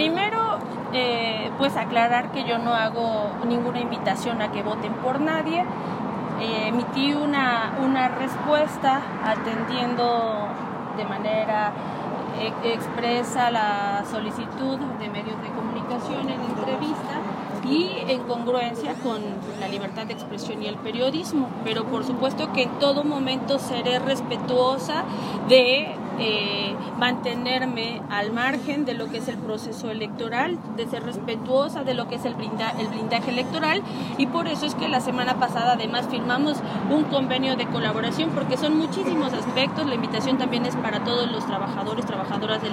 En entrevista posterior a la entrega de trabajos que se efectuaron de mantenimiento de La Recta a Cholula, Rivera Vivanco precisó que los celulares fueron decomisados mediante la aplicación de un operativo que se efectuó el pasado 20 de marzo entre el Grupo de Coordinación Territorial para la Construcción de la Paz, y en coordinación con la Secretaría de Gobernación Municipal, donde se aseguraron 231 equipos de telefonía móvil de dudosa procedencia que eran comercializados en el Centro Histórico por ambulantes.